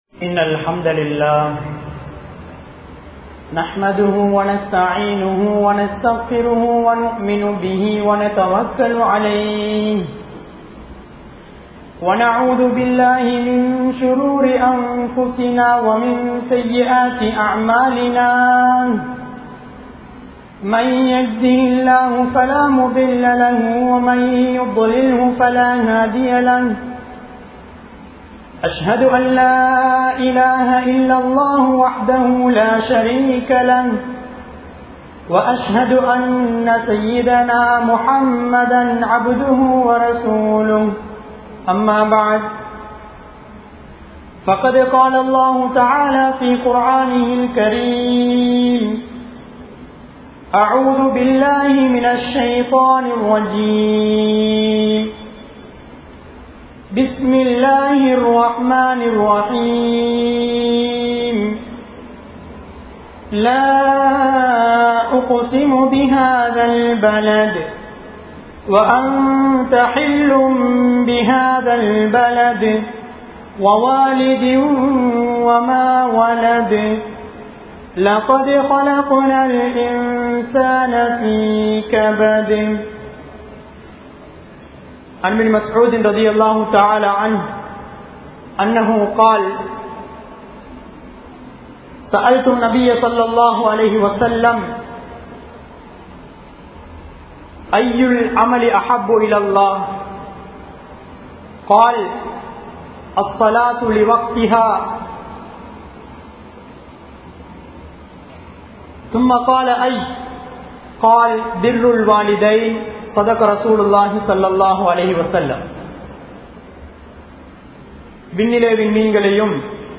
Pettroarhalukku Seiya Veandiyavai (பெற்றோர்களுக்கு செய்ய வேண்டியவை) | Audio Bayans | All Ceylon Muslim Youth Community | Addalaichenai
Gothatuwa, Jumua Masjidh 2019-12-20 Tamil Download